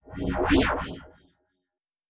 pgs/Assets/Audio/Sci-Fi Sounds/Movement/Synth Whoosh 5_1.wav at master
Synth Whoosh 5_1.wav